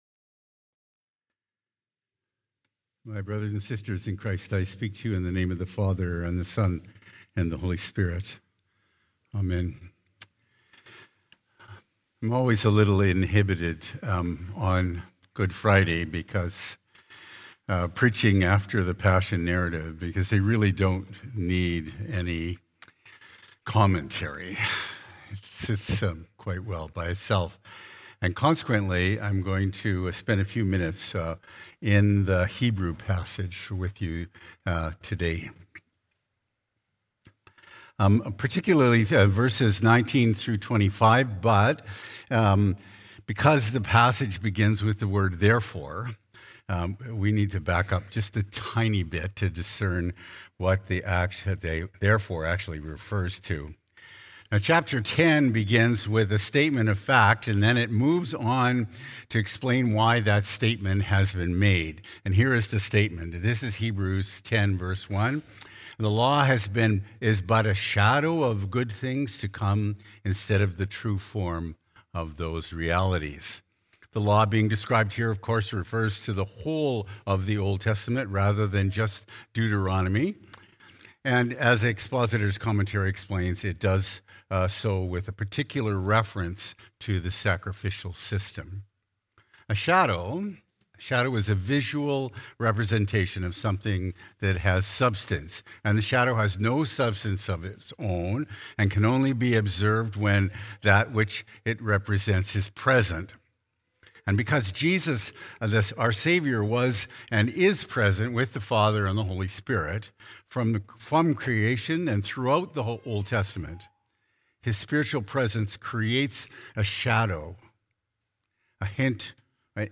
Current Sermon
Good Friday